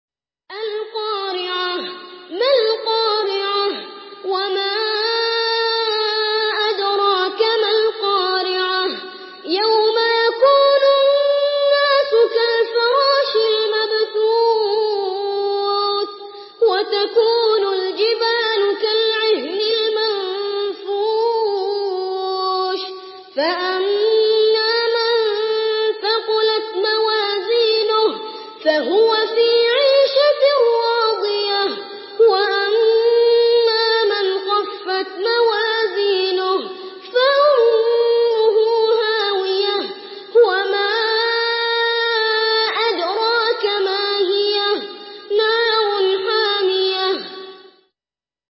Surah Karia MP3 by Muhammed Al Barrak in Hafs An Asim narration.
Murattal Hafs An Asim